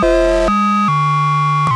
How is it possible that some samples (24bit AIFF stereo) leave a +/- 20 Hz tone (some at 1/3 of output in status window) after playback (without loop checked)?